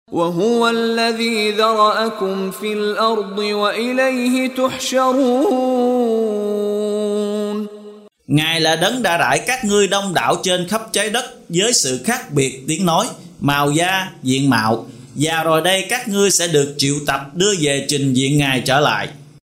Đọc ý nghĩa nội dung chương Al-Muminun bằng tiếng Việt có đính kèm giọng xướng đọc Qur’an